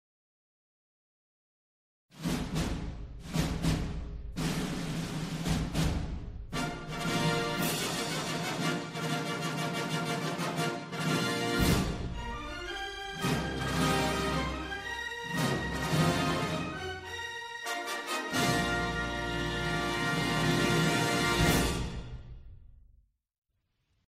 iconic, triumphant introduction music